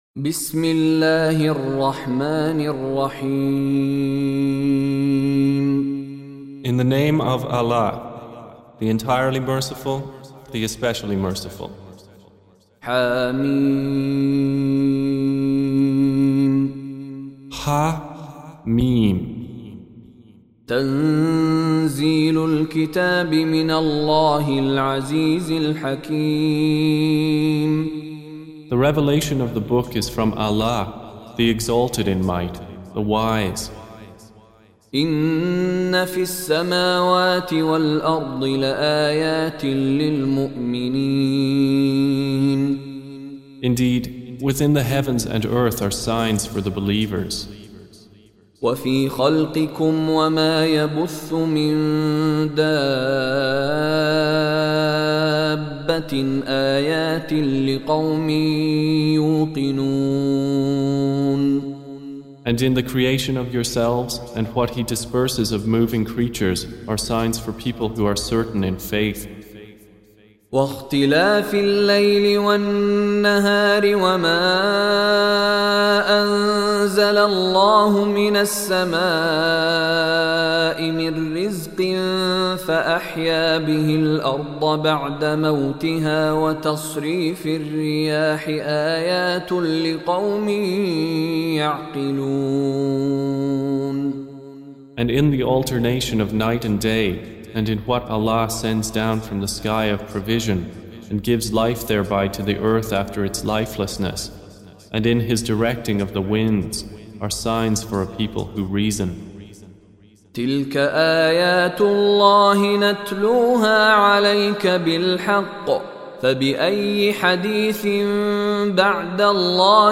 Surah Repeating تكرار السورة Download Surah حمّل السورة Reciting Mutarjamah Translation Audio for 45.